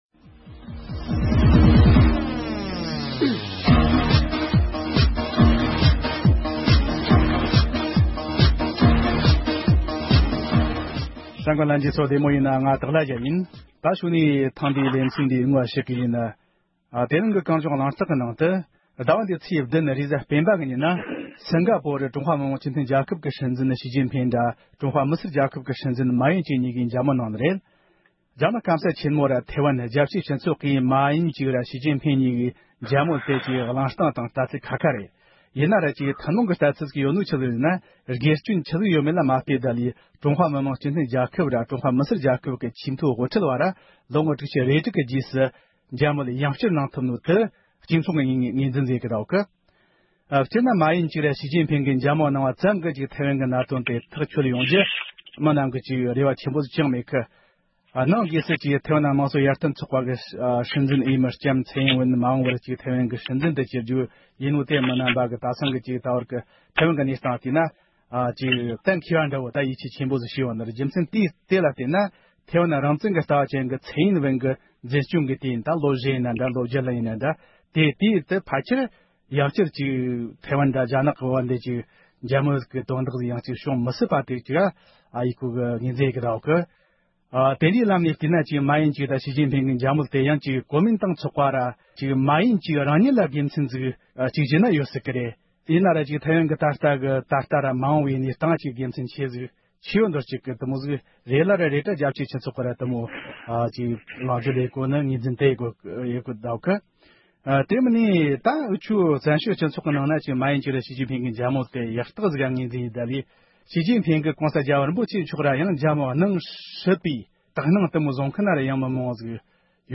འབྲེལ་ཡོད་མི་སྣ་དང་ལྷན་དུ་བགྲོ་གླེང་ཞུས་པར་གསན་རོགས་ཞུ༎